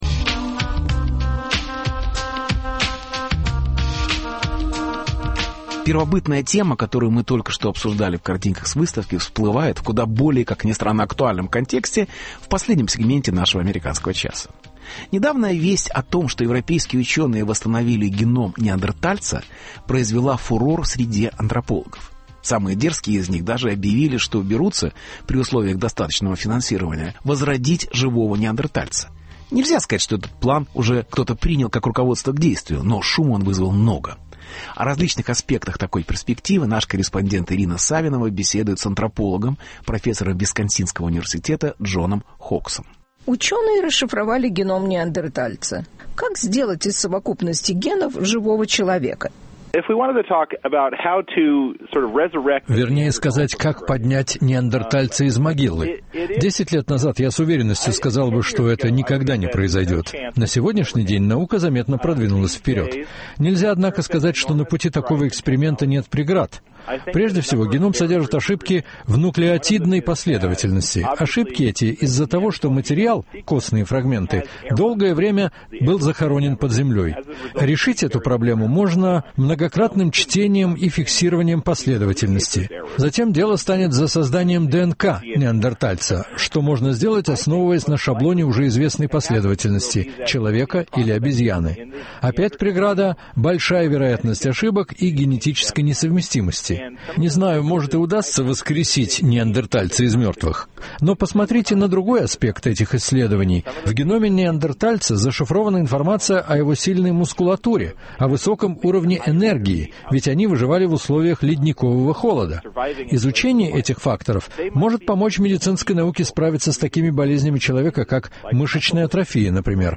Интервью. Живой неандерталец.